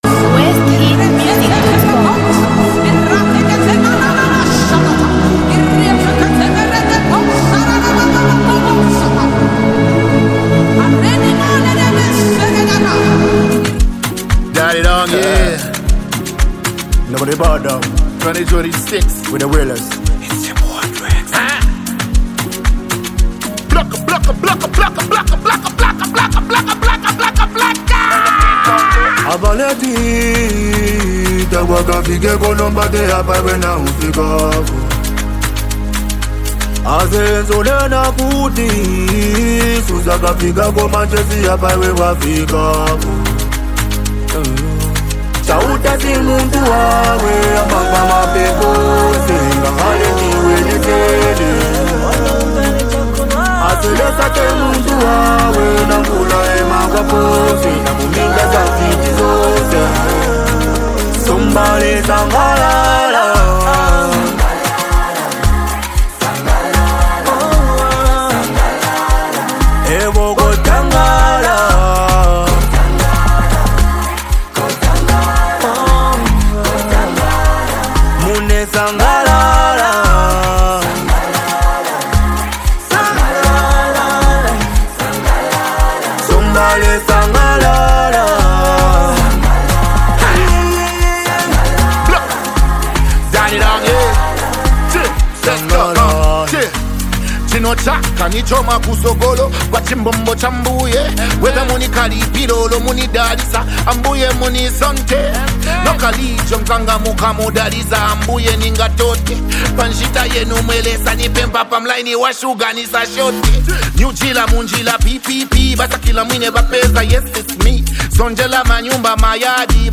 Zambia Music